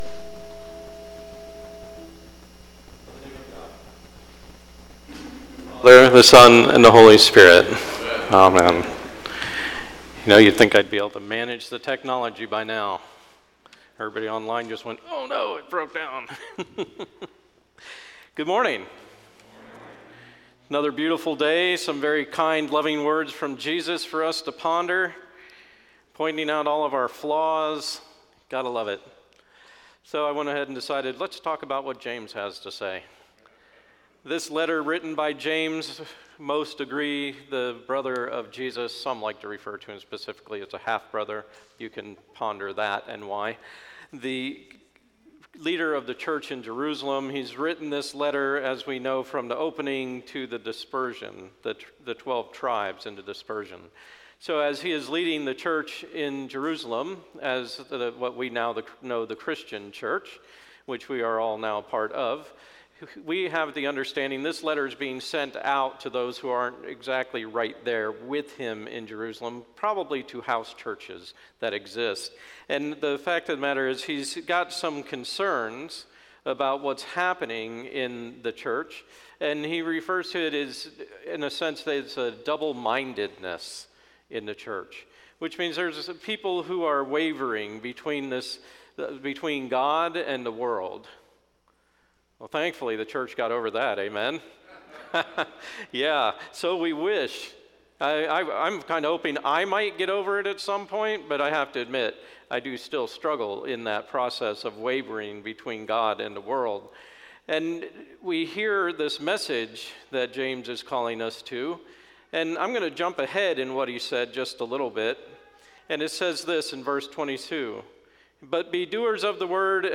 Sermon, 8/29/21, Fourteenth Sunday after Pentecost - Holy Innocents' Episcopal Church
Sermon, 8/29/21, Fourteenth Sunday after Pentecost